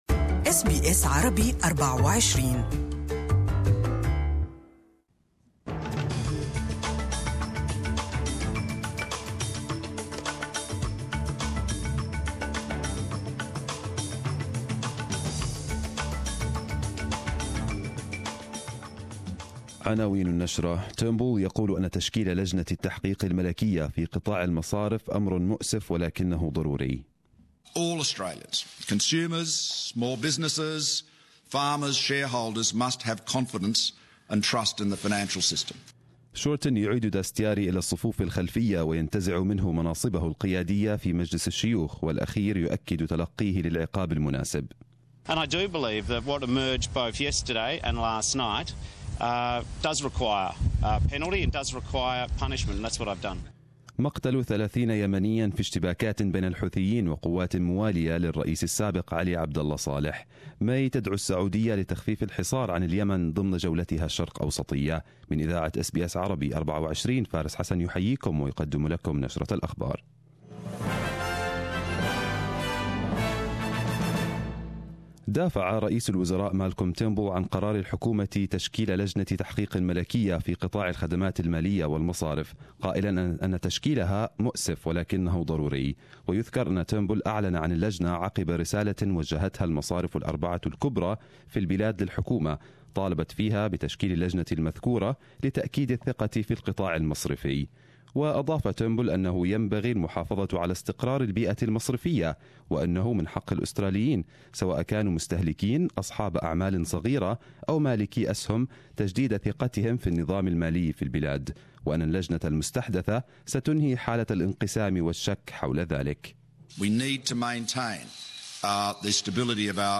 Arabic News Bulletin 01/12/2017